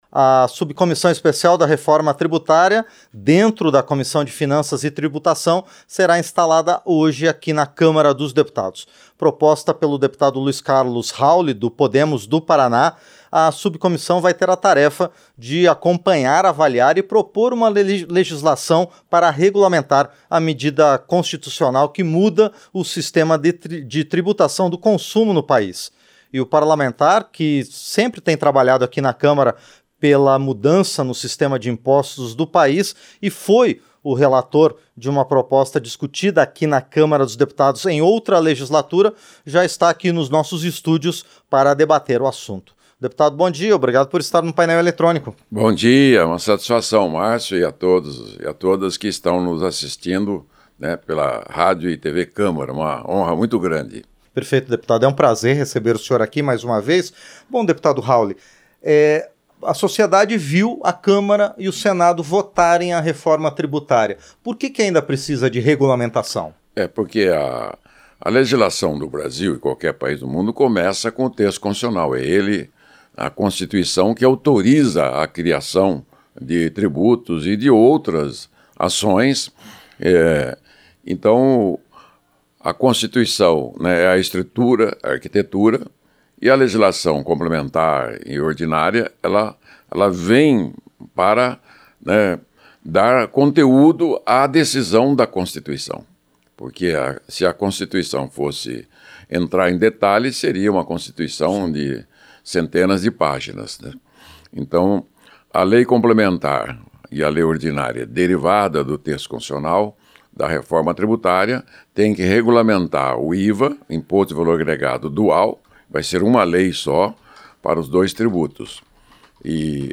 Entrevista - Dep. Luiz Carlos Hauly (Podemos-PR)